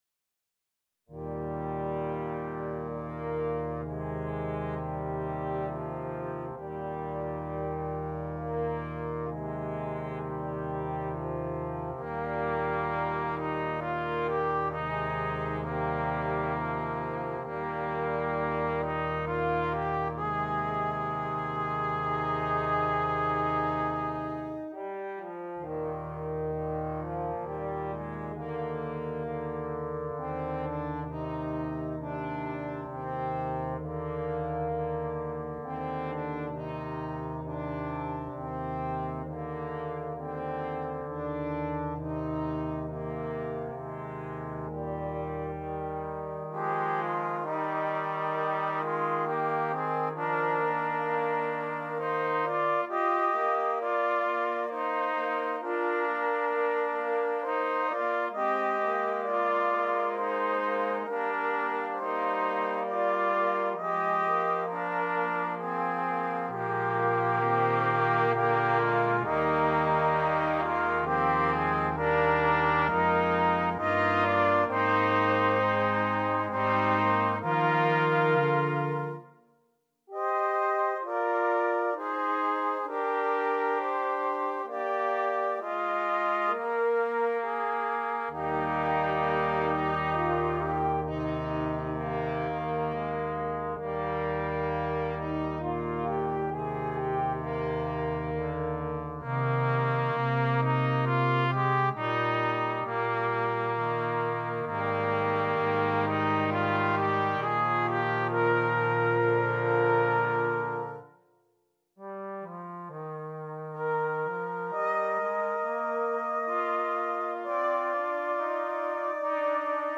Brass Quintet
Traditional Carol